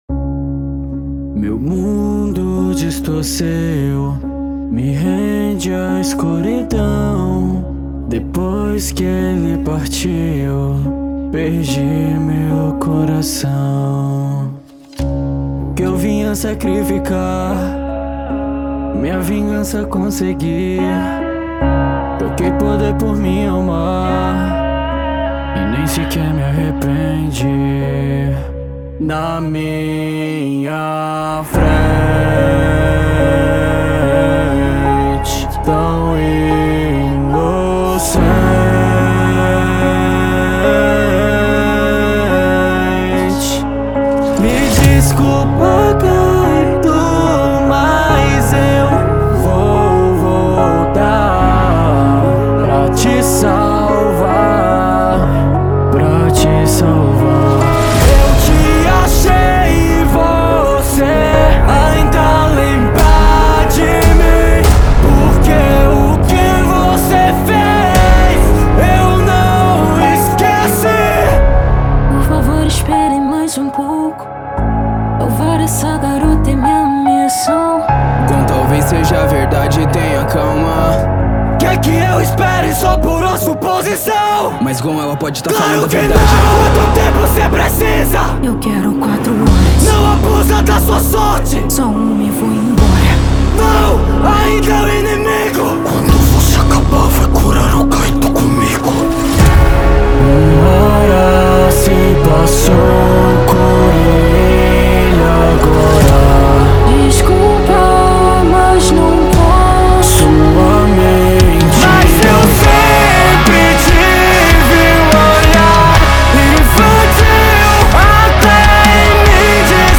2025-02-23 14:52:45 Gênero: Rap Views